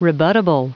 Prononciation du mot rebuttable en anglais (fichier audio)
Prononciation du mot : rebuttable